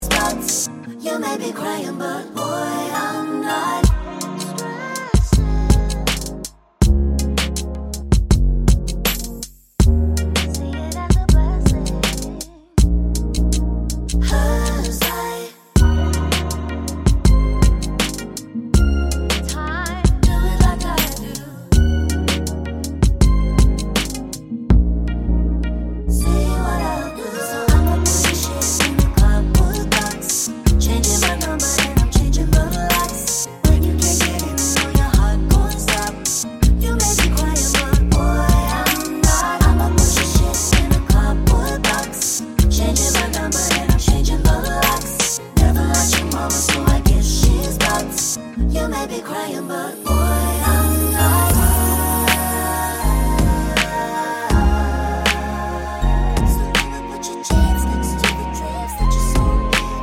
With Explicit Backing Vocals Pop (2020s) 2:46 Buy £1.50